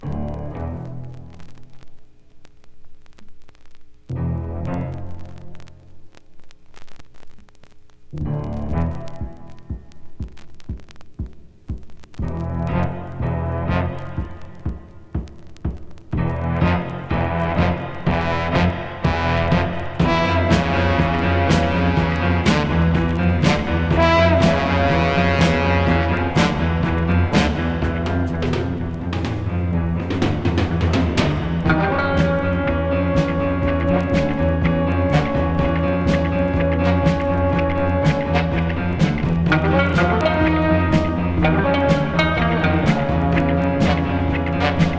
音の豪勢さと大人の遊びみたいな印象すらあるエンタメ感満載なレコード。
Rock, Stage & Screen　USA　12inchレコード　33rpm　Mono